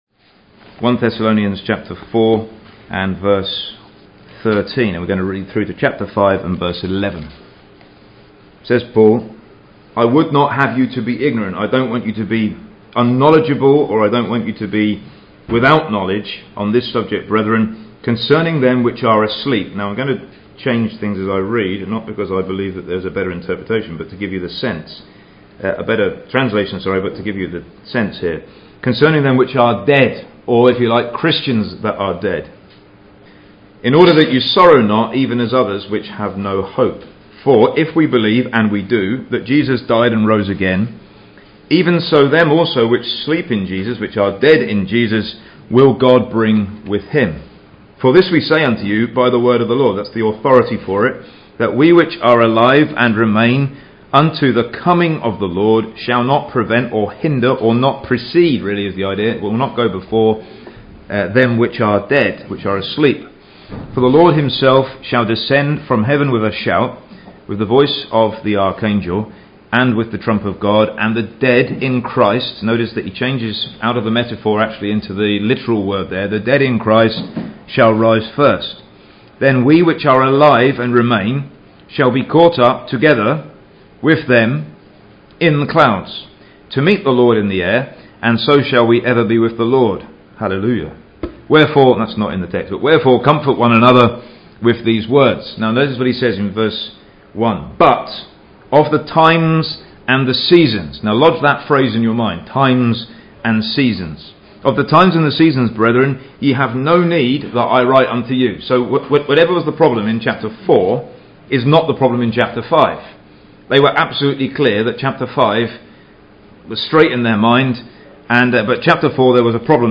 (Message preached 8th Nov 2015)